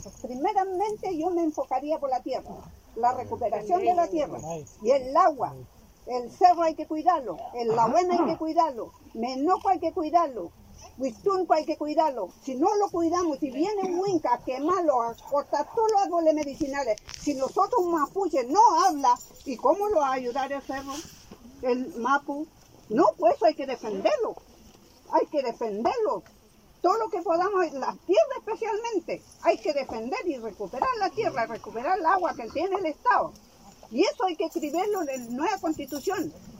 El pasado lunes 22 de febrero la Machi Francisca Linconao fue invitada a participar del Xawvn Kvzaw en el marco del proceso constituyente, convocado por el histórico Parlamento Mapunche de Koz-koz en el territorio de Panguipulli.